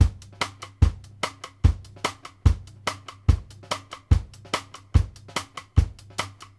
Index of /90_sSampleCDs/Sampleheads - New York City Drumworks VOL-1/Partition F/SP REGGAE 72
JUST HANDS-R.wav